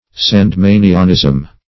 Search Result for " sandemanianism" : The Collaborative International Dictionary of English v.0.48: Sandemanianism \San`de*ma"ni*an*ism\, n. The faith or system of the Sandemanians.